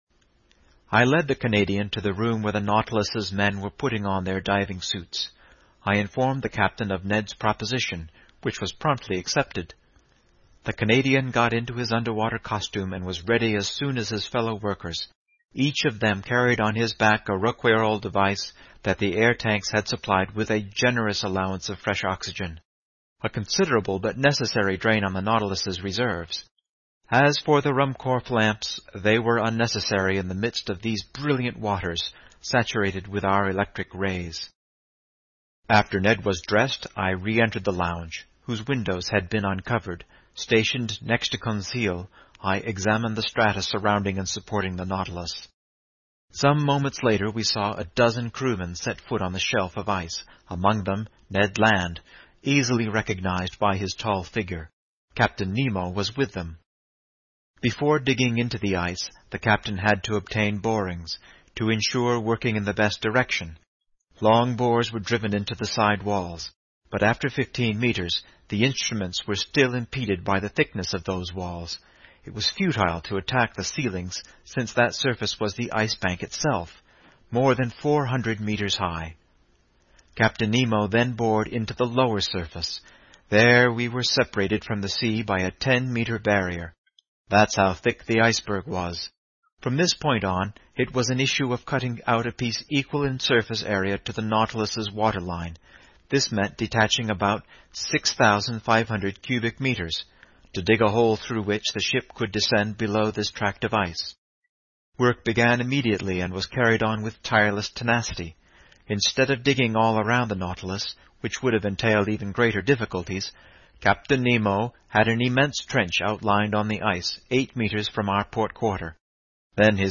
在线英语听力室英语听书《海底两万里》第470期 第29章 缺少空气(2)的听力文件下载,《海底两万里》中英双语有声读物附MP3下载